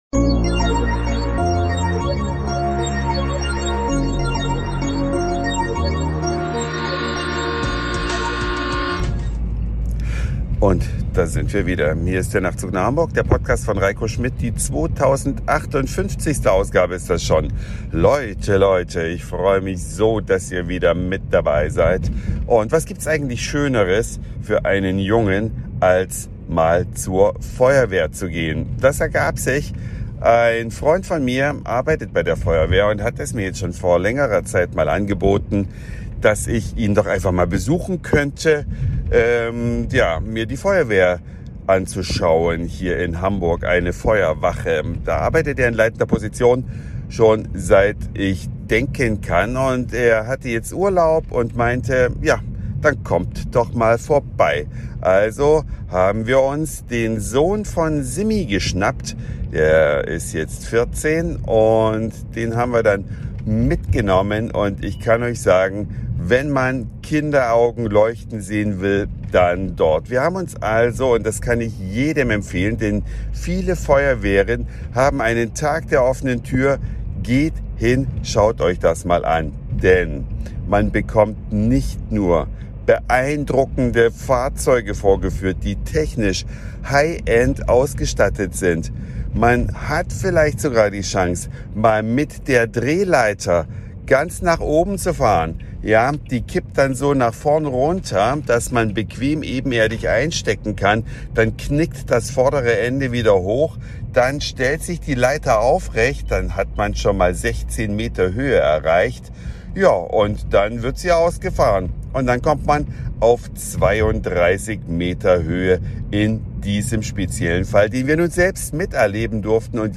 Eine Reise durch die Vielfalt aus Satire, Informationen, Soundseeing und Audioblog.
Auf der Feuerwache in Hamburg